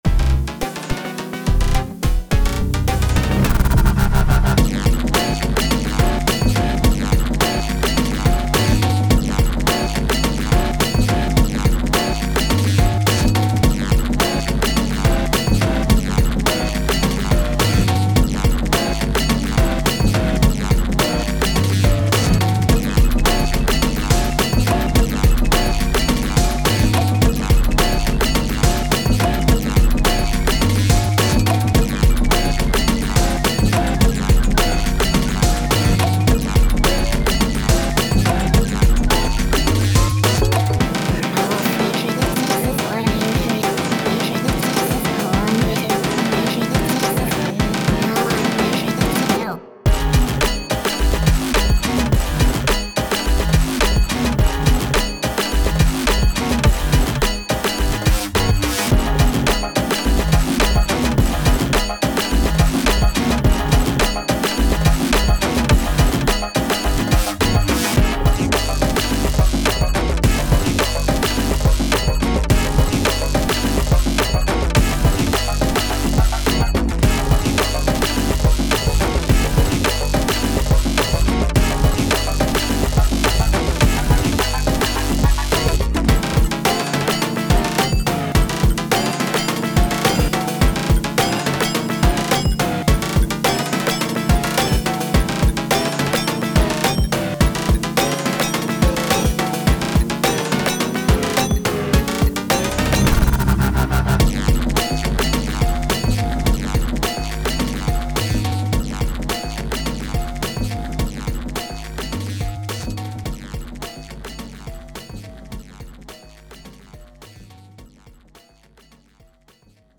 近未来感のある変わった雰囲気のGlitch Hop楽曲。